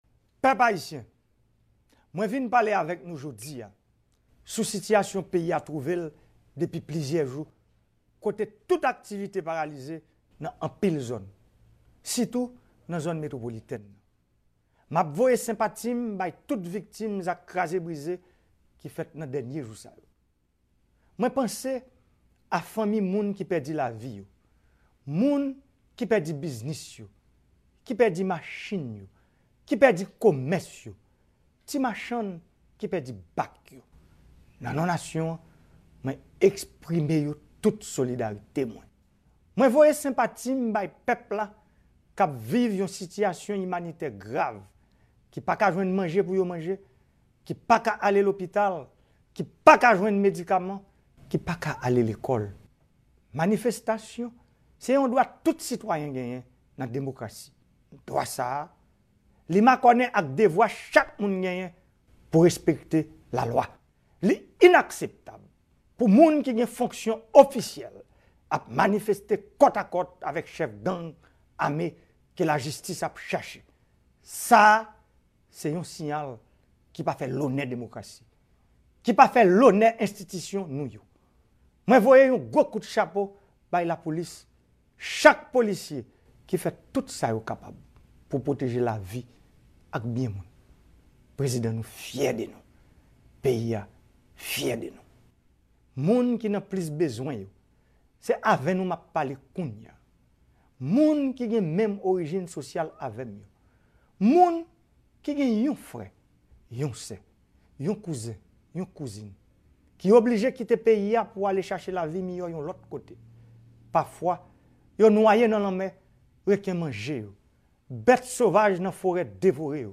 Mesaj Prezidan Jovenel Moise pou pèp ayisyen an nan mitan kriz politik e sosyal la